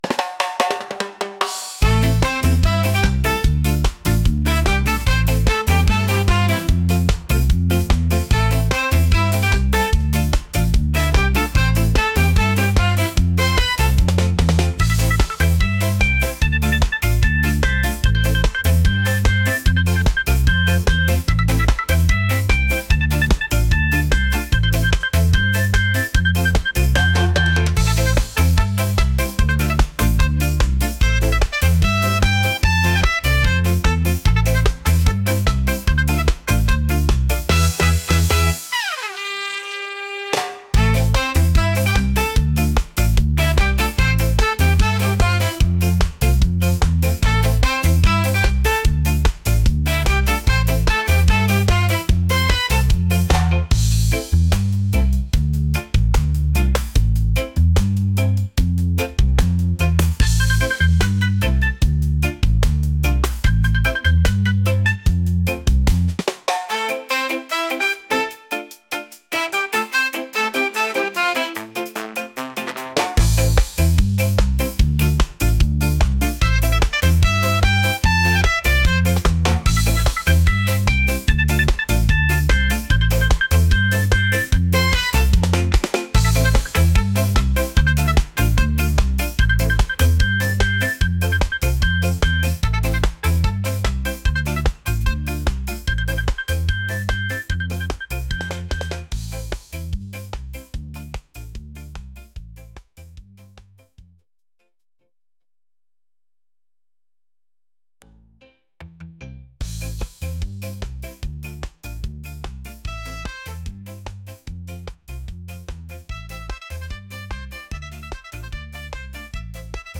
reggae | energetic | upbeat